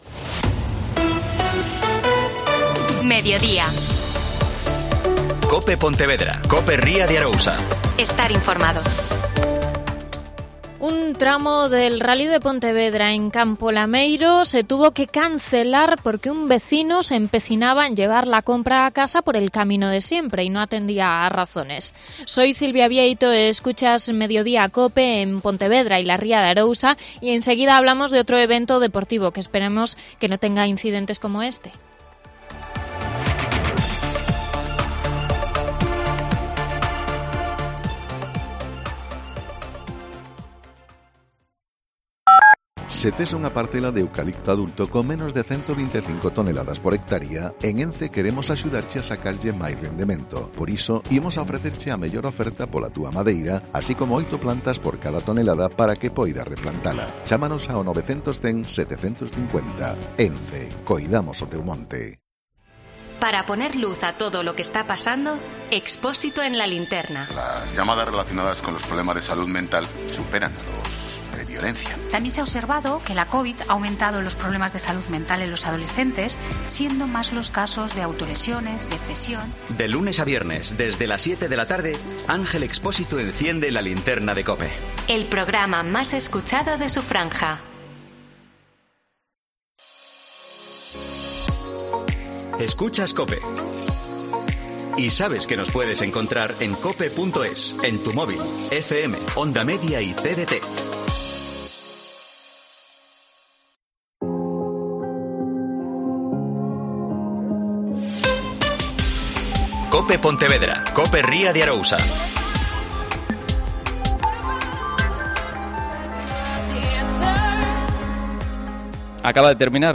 Alcaldesa y alcaldes en funciones de Meis, Meaño y Cambados respectivamente.